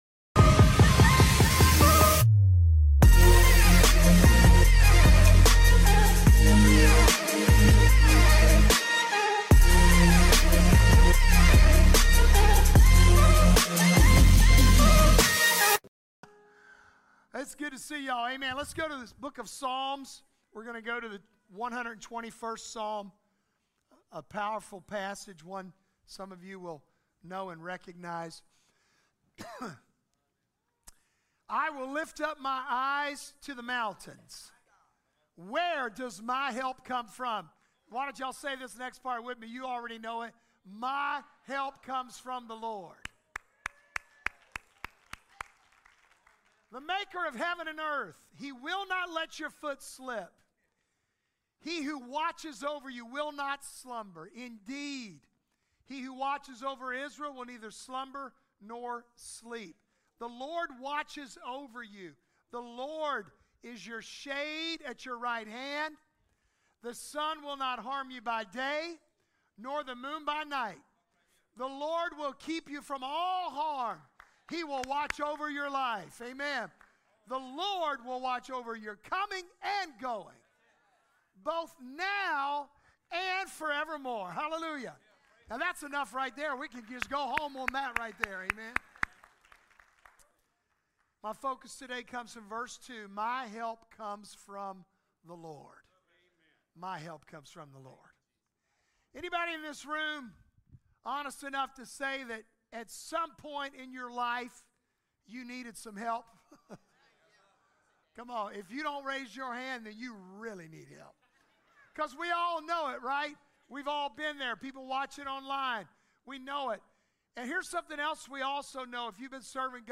Focusing on Psalm 121, we explore the comforting assurance that God is our ever-present help in times of trouble. This sermon challenges us to approach God's throne with confidence, knowing that as His children, we have direct access to His grace and mercy. The message beautifully contrasts the peace that God offers with the fear that often plagues our lives, encouraging us to exchange our anxieties for His supernatural peace.